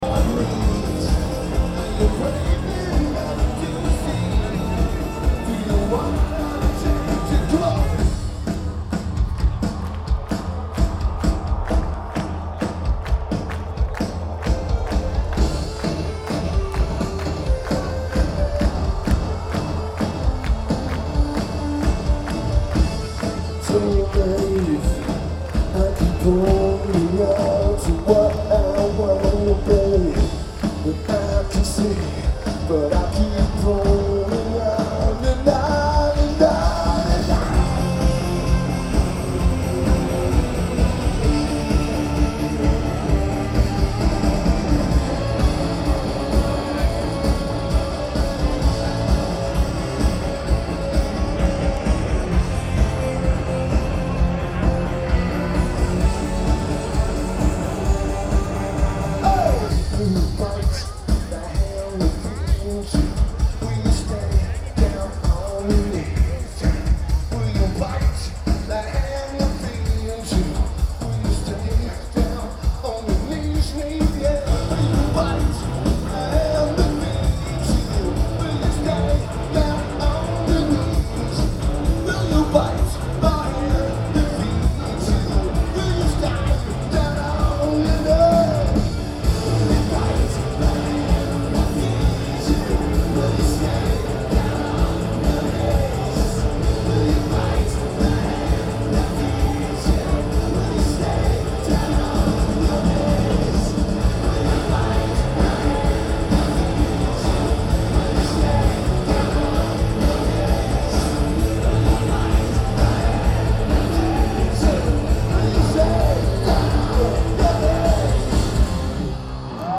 DTE Energy Music Theatre
Drums
Bass
Vocals/Guitar/Keyboards
Lineage: Audio - AUD (DPA 4061 + MPS 6030 + Edirol R-09)